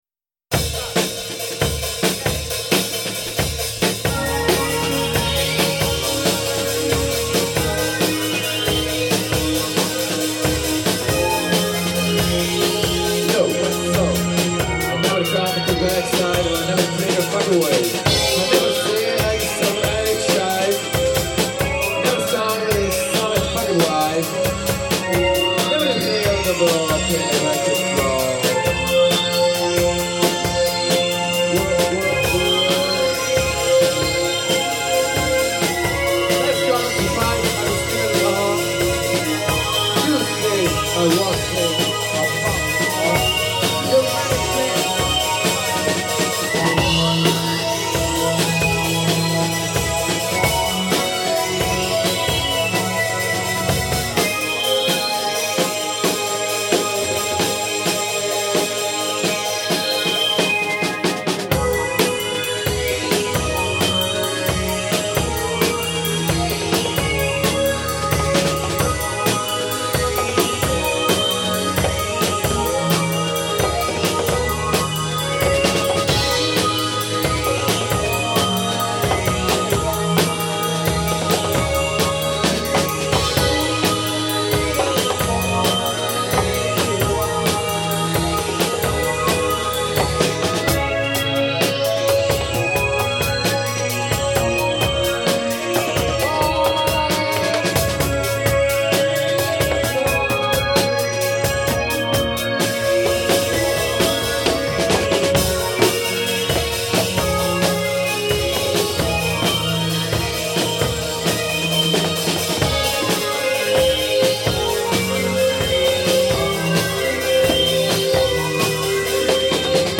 Live jam-session in Riva San Vitale (CH)